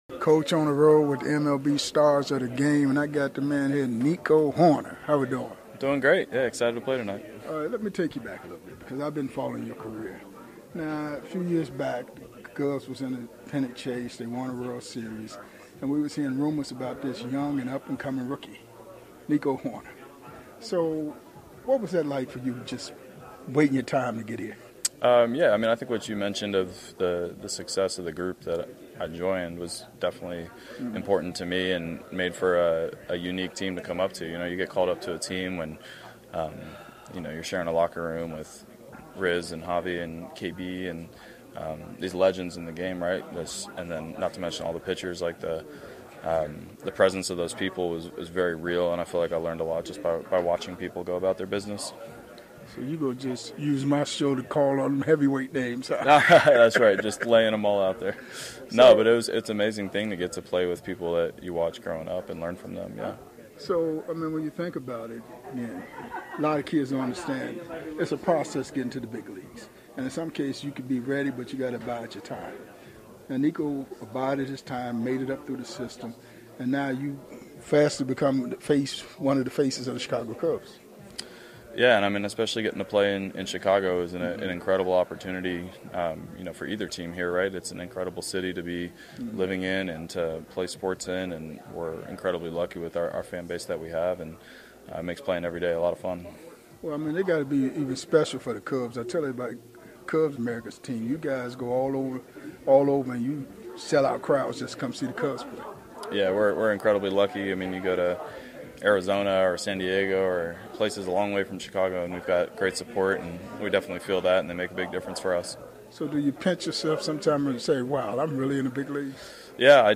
⚾ MLB Classic Interviews – Coaches Corner ⚾
one on one with the MLB stars of the game